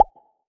Bubble Pop Shoot v2.wav